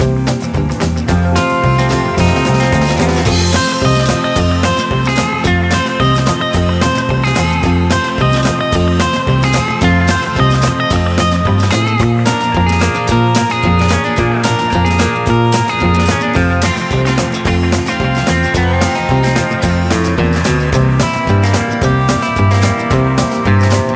Country (Male) 2:24 Buy £1.50